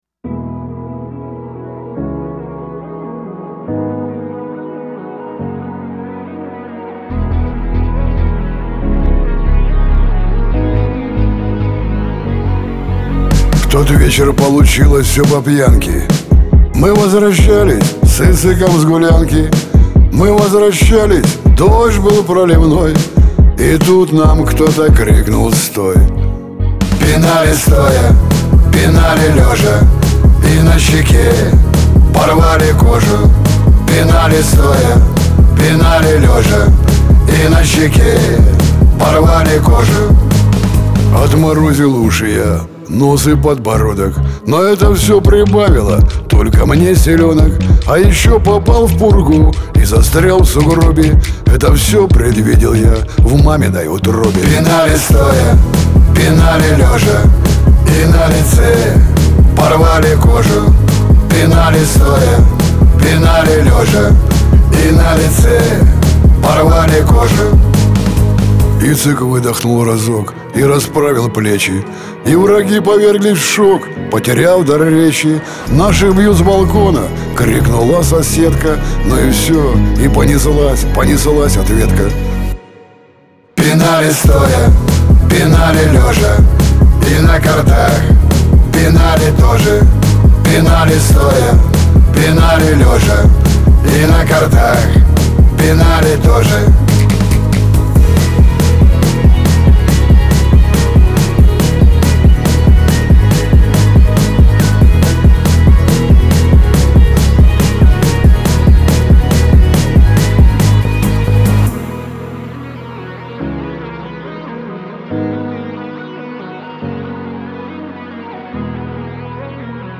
Лирика
Кавказ – поп